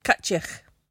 [kUHt-yeeachk]